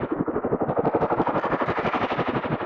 Index of /musicradar/rhythmic-inspiration-samples/90bpm